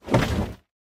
creatura_hit_3.ogg